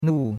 nu4.mp3